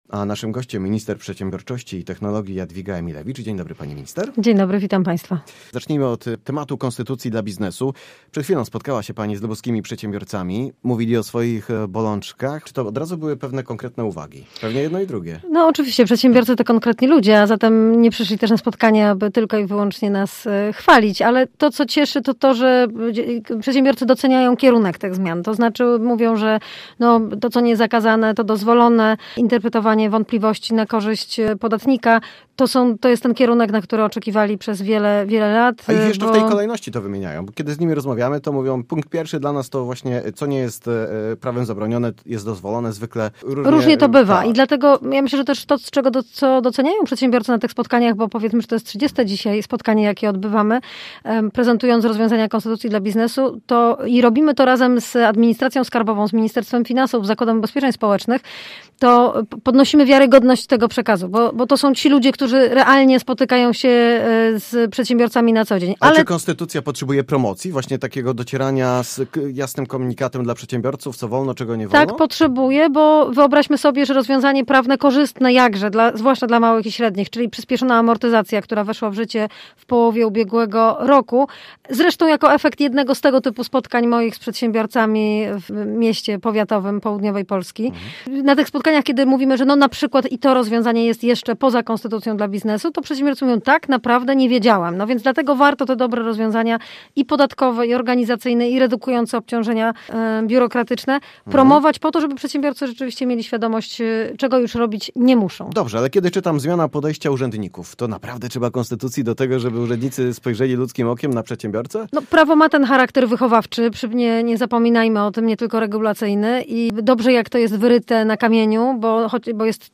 rozmowa-z-minister-emilewicz.mp3